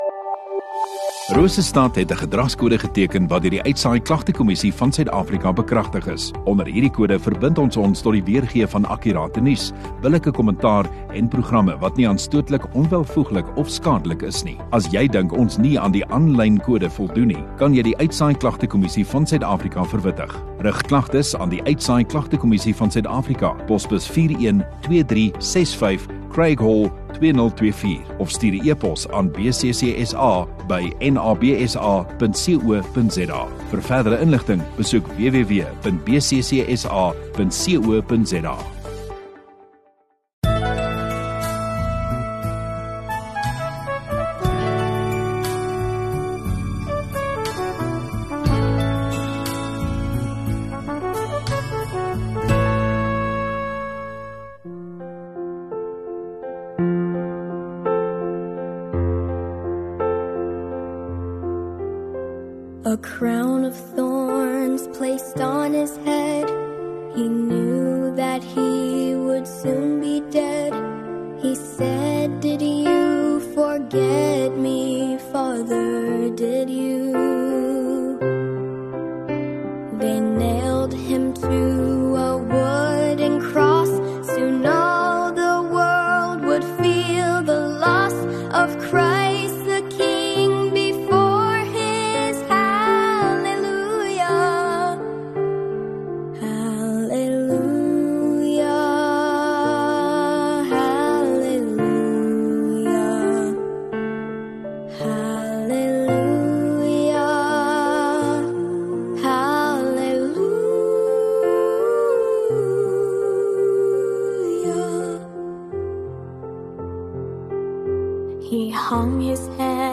View Promo Continue Radio Rosestad Install Rosestad Godsdiens 3 Apr Vrydagaand Erediens